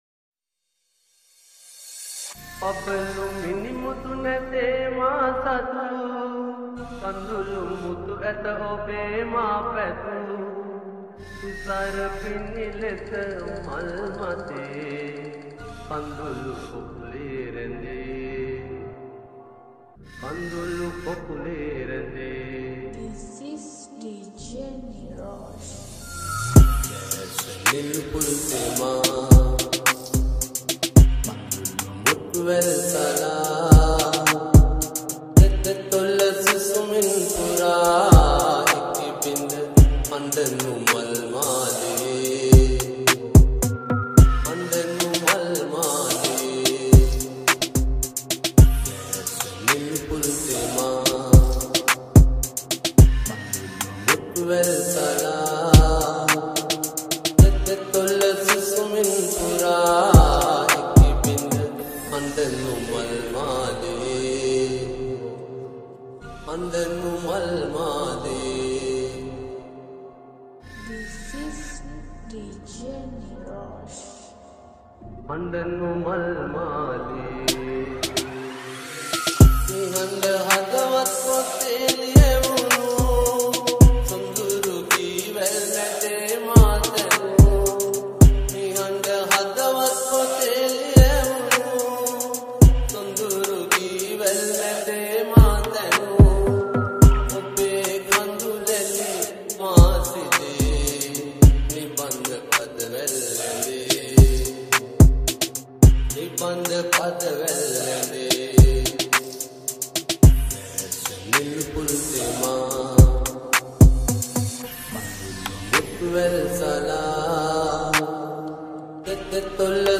Remix New Song
Bass Boosted